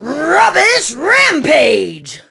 pam_lead_vo_03.ogg